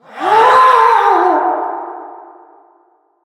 balloon_ghost_wail_04.ogg